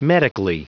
Prononciation du mot medically en anglais (fichier audio)
Prononciation du mot : medically